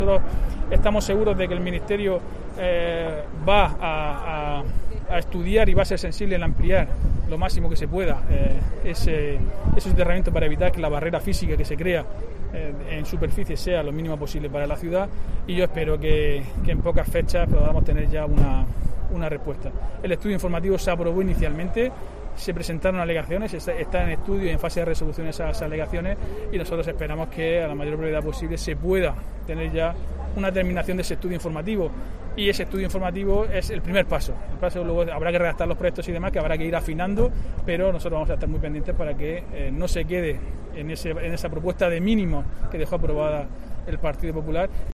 Diego José Mateos, alcalde de Lorca sobre AVE